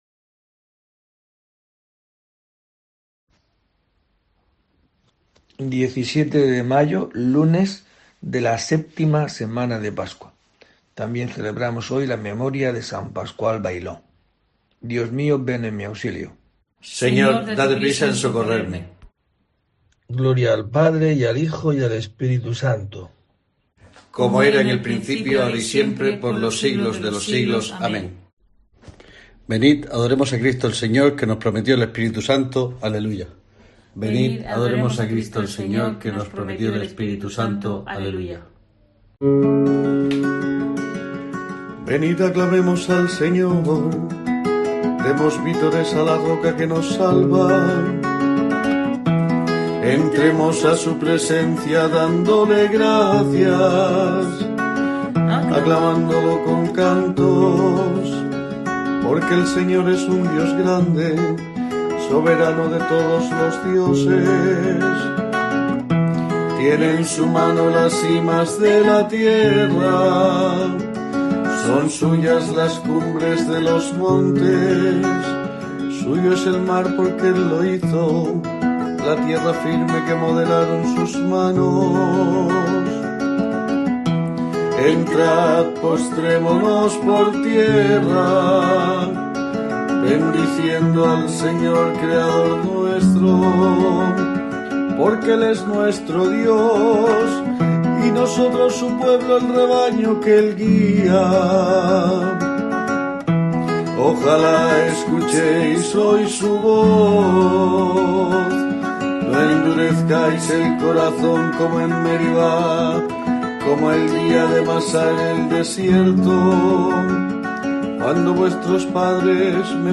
La oración desde la parroquia de Santa Eulalia de Murcia, pronunciada en este día